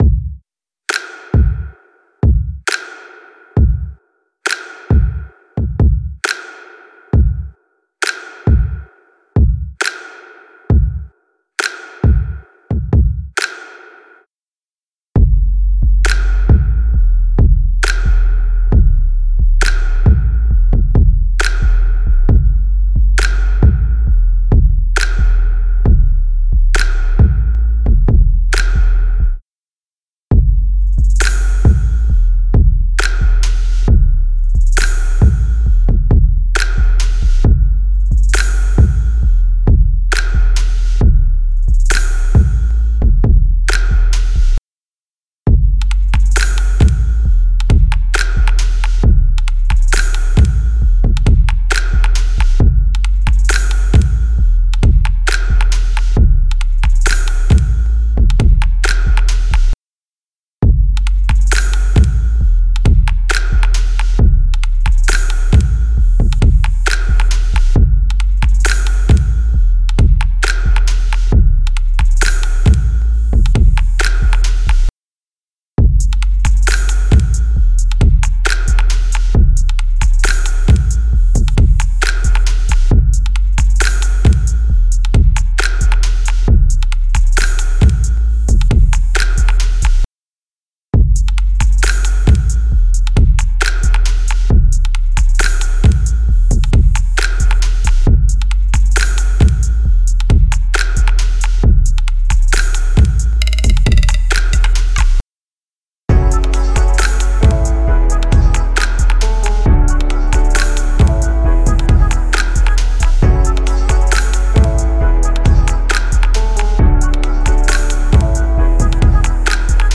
Chill Trap
Hier stehen weichere Sounds und zahlreiche Gesang-Parts anstelle von markanten Rap-Einlagen im Vordergrund.
So entstammen einige Percussion-Sounds unseres Demo-Beats den Aufnahmen von Steinen, die in ein Flussbett geworfen wurden.
Die Mitten des Kick-Samples sind kräftig verstärkt. So wird ein sehr bauchiger Charakter erzielt, der sich gut gegen den massiven Subbass des Beats durchsetzt.
Ein Halftime-Pattern, kombiniert mit schnellen Hi-Hat-Figuren, sind wesentliche rhythmische Bestandteile unseres »Chill-Trap«-Beats.